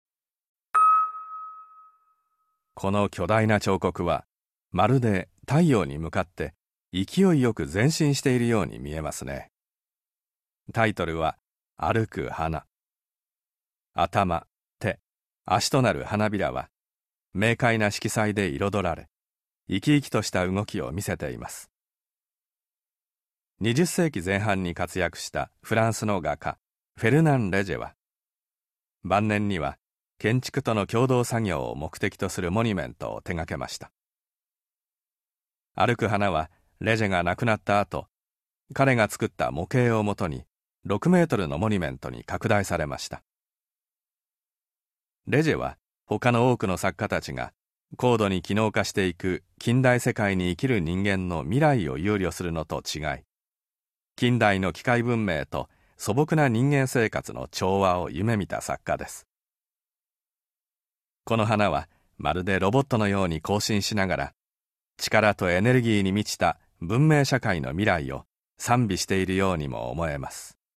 箱根 彫刻の森美術館 THE HAKONE OPEN-AIR MUSEUM - 音声ガイド - フェルナン・レジェ 歩く花 1952年